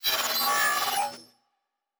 Sci-Fi Sounds / Electric / Data Calculating 5_2.wav
Data Calculating 5_2.wav